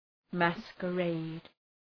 Προφορά
{,mæskə’reıd}